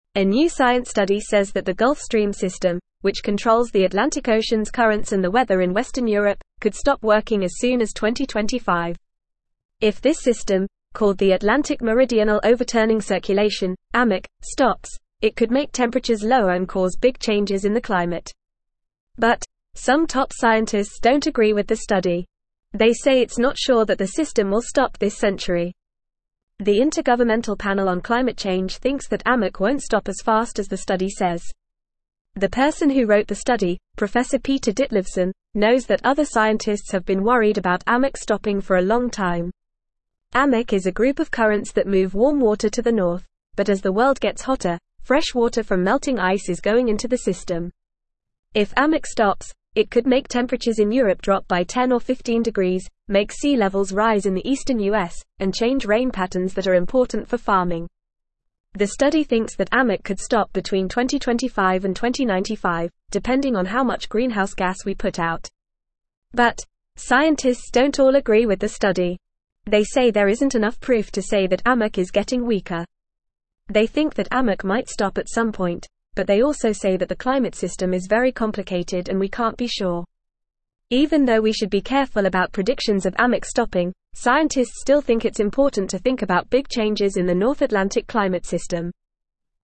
Fast
English-Newsroom-Lower-Intermediate-FAST-Reading-Gulf-Stream-System-May-Stop-Changing-Climate.mp3